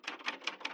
terminal_text_bass.wav